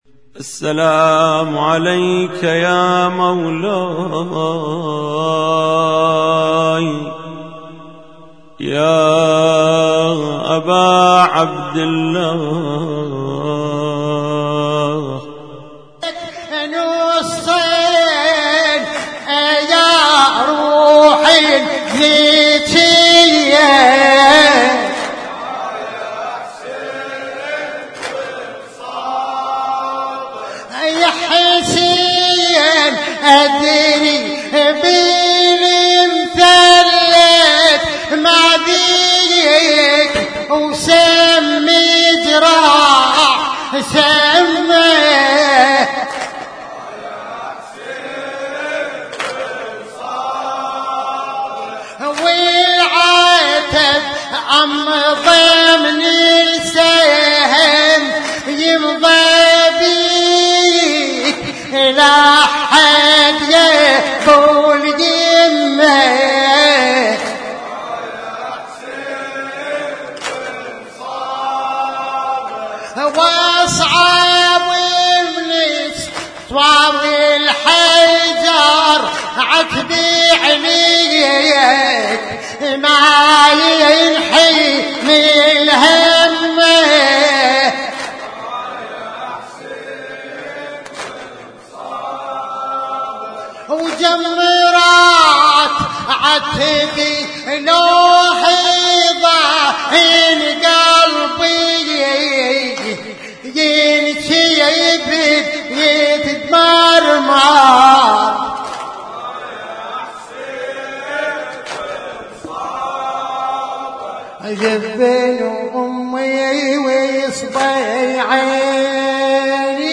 Husainyt Alnoor Rumaithiya Kuwait
لطم ليله الاربعين - شهر صفر 1436